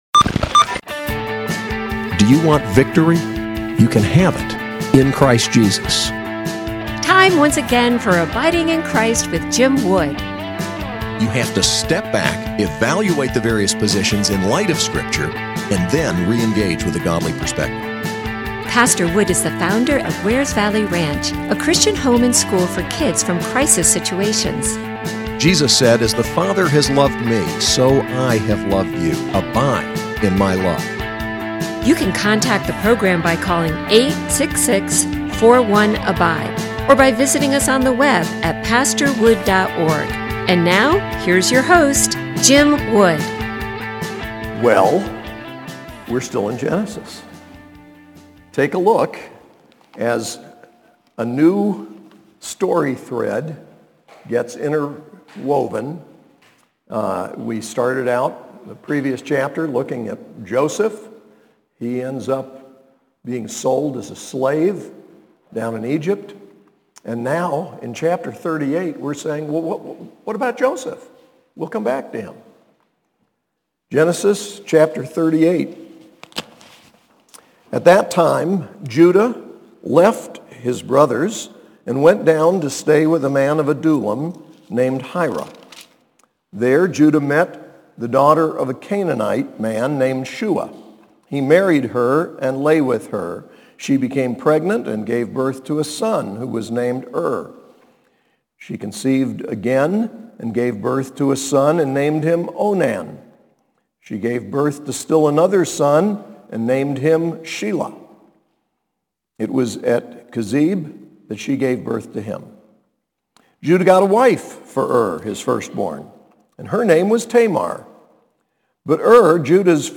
SAS Chapel: Genesis 38